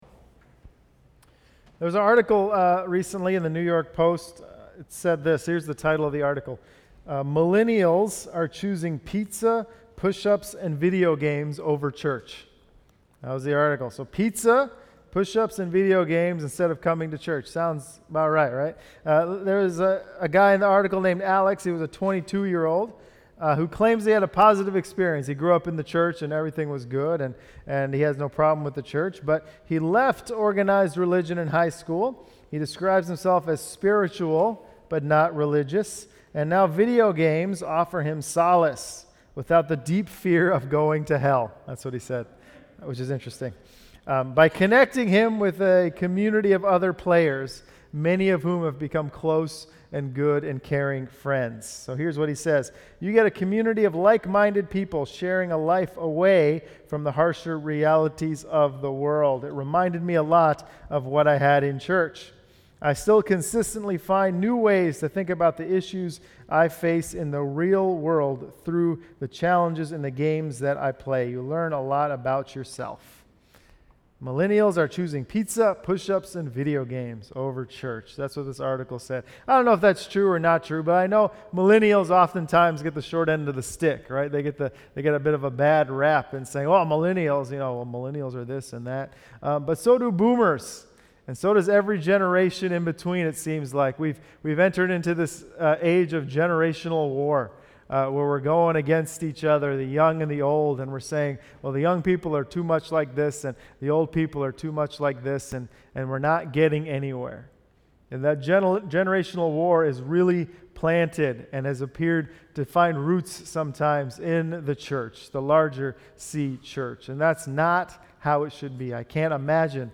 10 Sept Sermon.mp3